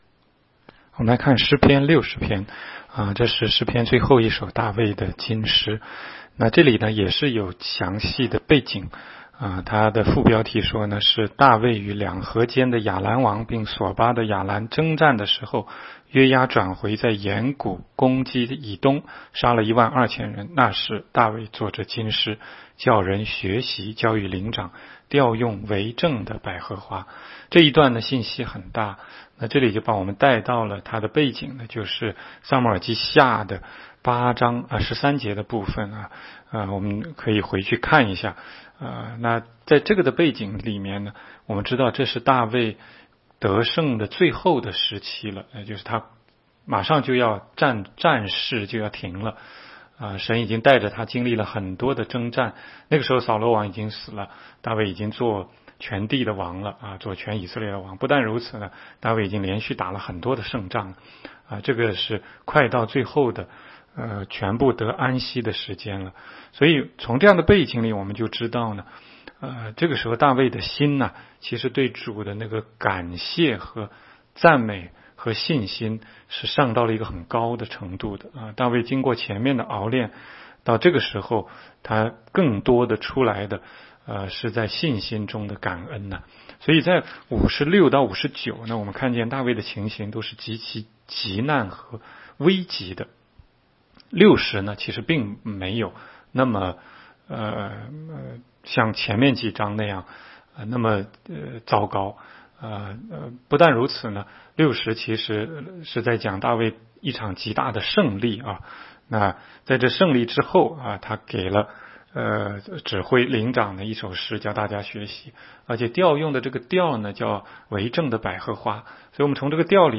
16街讲道录音 - 每日读经-《诗篇》60章